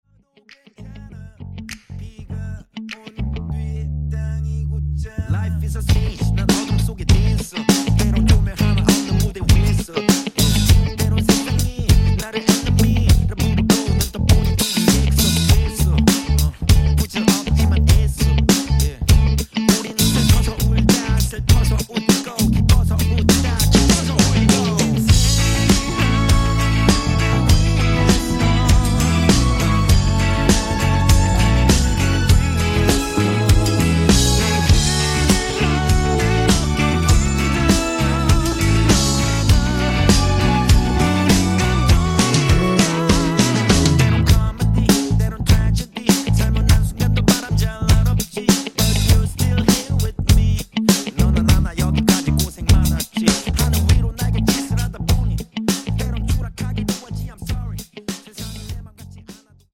음정 원키 3:40
장르 가요 구분 Voice Cut